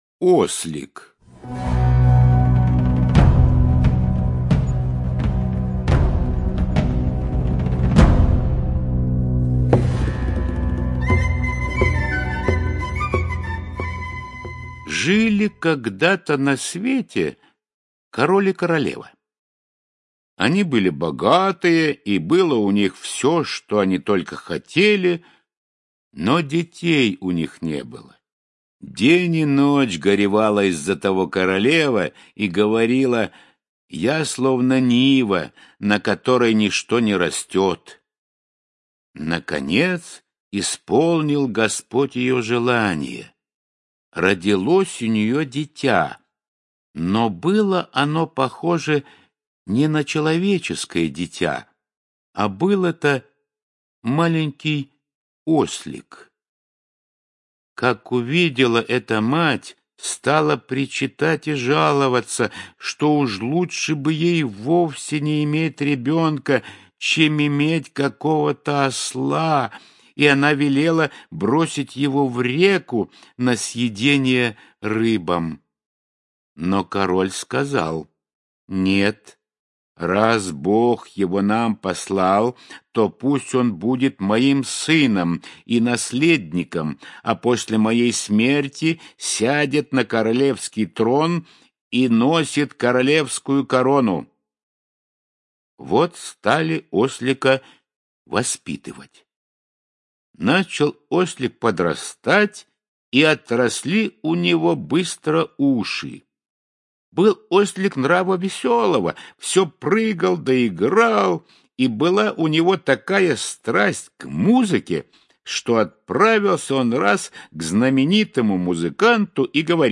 Ослик - аудиосказка братьев Гримм. Сказка о короле и королеве, у которых было всего вдоволь, кроме детей.